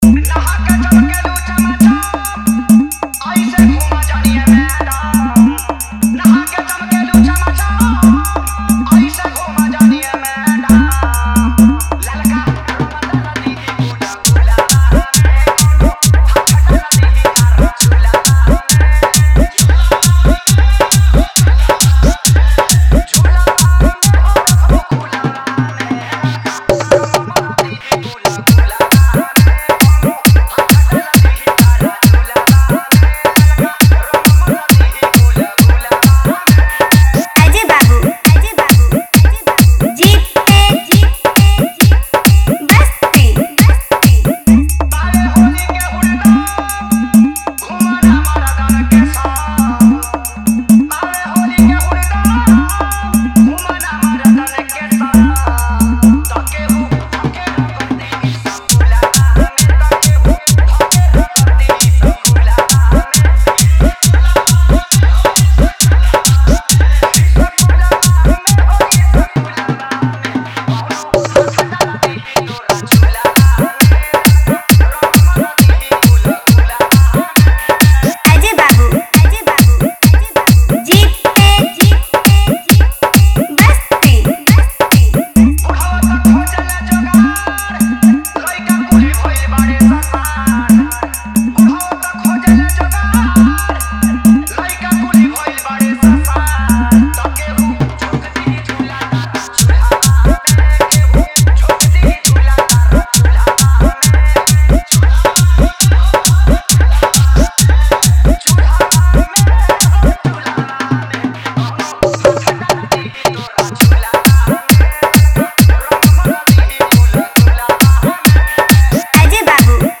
Category : Holi 2025 Wala Dj Remix